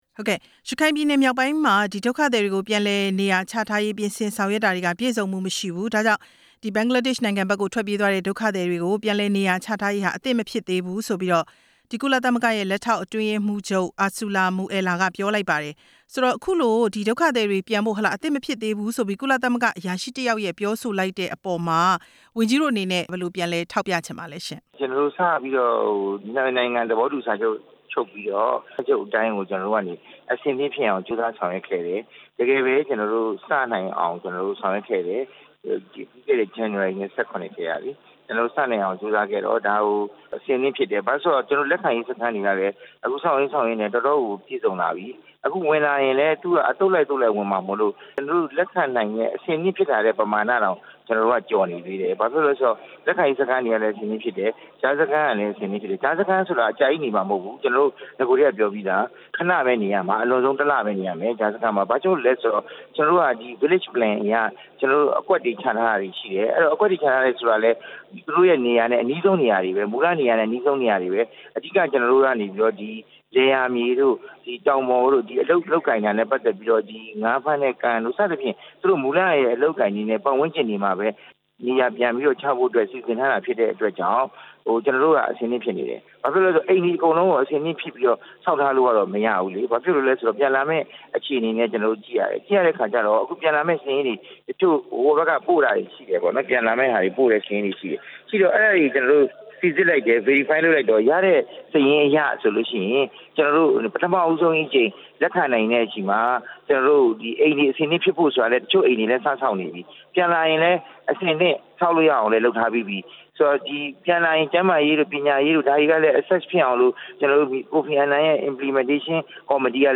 ဒေါက်တာ ဝင်းမြတ်အေးနဲ့ ဆက်သွယ်မေးမြန်းချက်